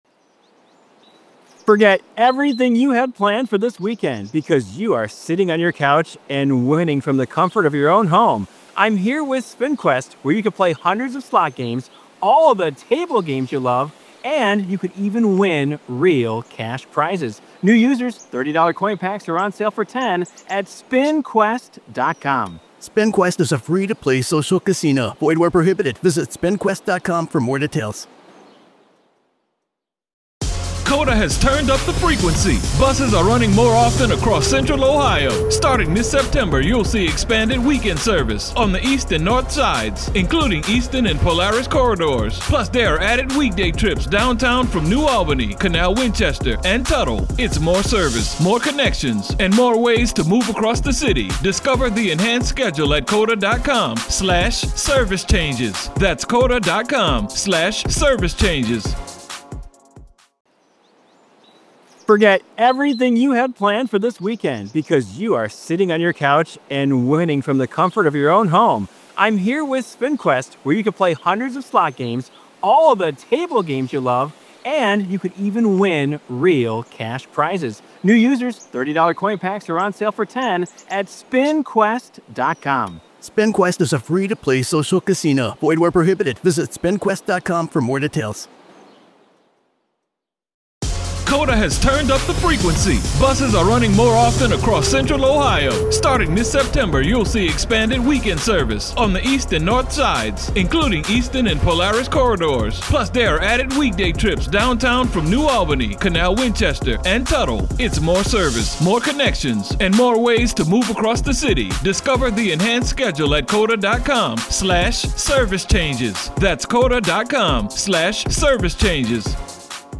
The interview delves into the twisted mindset of sex offenders who manipulate children, convincing them that their actions are expressions of love.